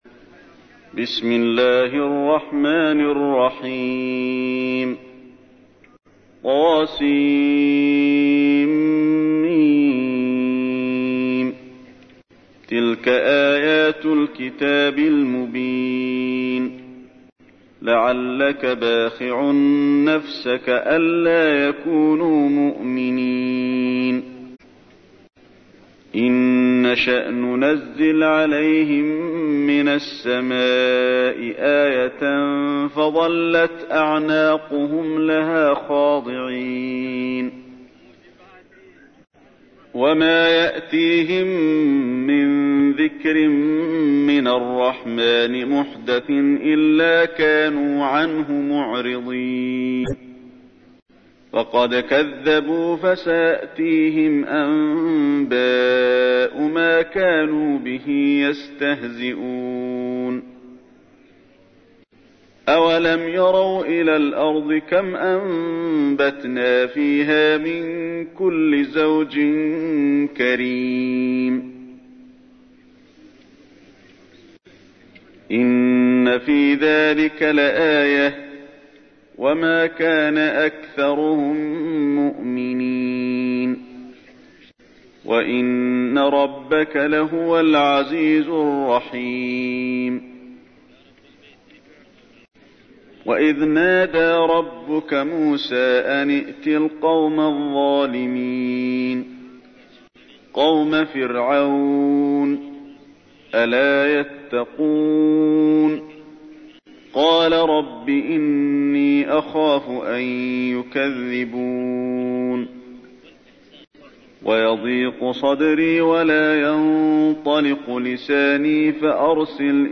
تحميل : 26. سورة الشعراء / القارئ علي الحذيفي / القرآن الكريم / موقع يا حسين